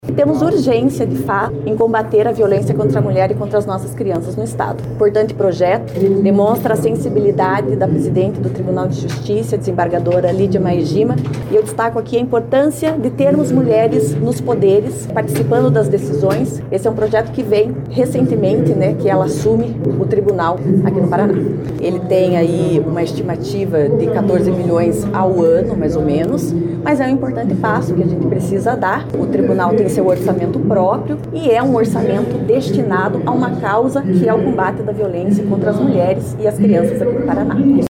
A deputada Mabel Canto (PSDB), escolhida para ser a relatora do projeto, falou sobre a importância desse projeto para as mulheres que buscam Justiça no estado. Mabel Canto também ressaltou que o projeto prevê a criação de cinco cargos de desembargador, dois cargos de juiz de Direito Substituto em Segundo Grau e cargos de provimento em comissão e funções de confiança, mas há orçamento disponível.